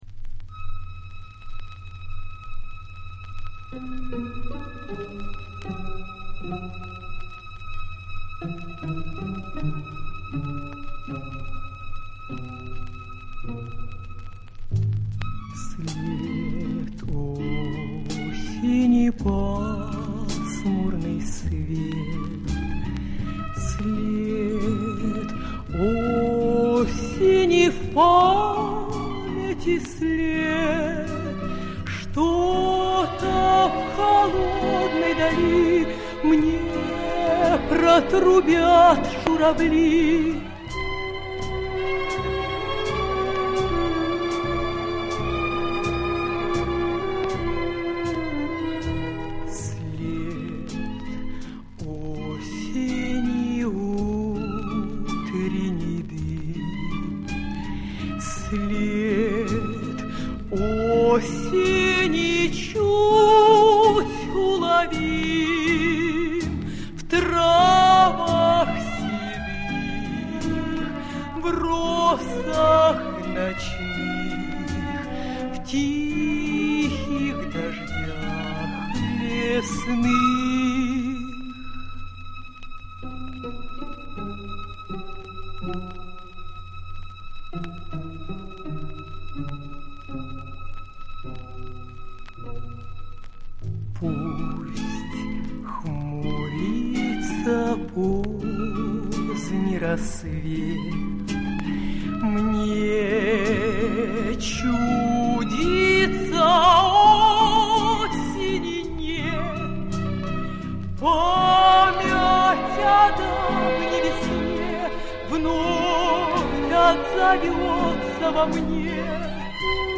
пластинка
Format:  Flexi-disc, 7", 33 ⅓ RPM, Mono
Genre:  Pop